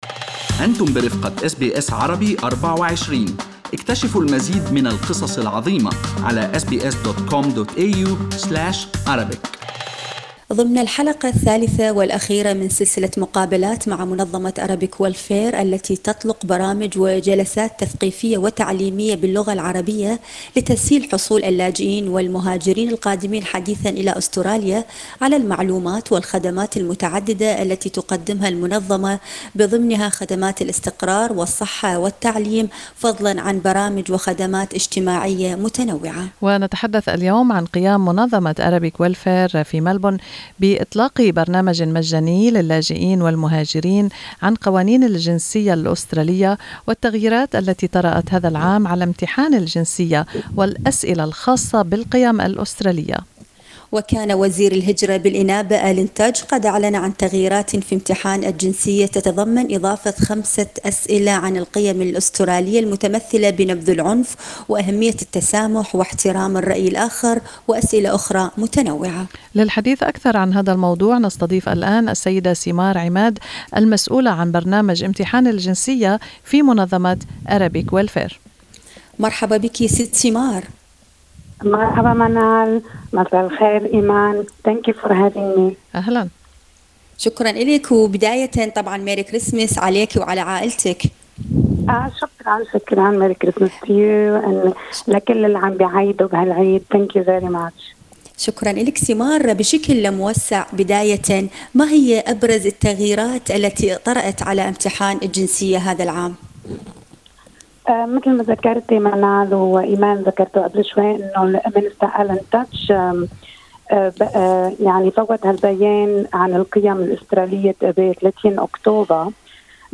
ضمن الحلقة الثالثة والأخيرة من سلسلة مقابلات مع منظمة Arabic Welfare التي تطلق برامج وجلسات تثقيفية وتعليمية باللغة العربية لتسهيل حصول اللاجئين والمهاجرين القادمين حديثا إلى أستراليا على المعلومات والخدمات المتعددة التي تقدمها المنظمة بضمنها خدمات الإستقرار والصحة والتعليم، فضلا عن برامج وخدمات اجتماعية متنوعة.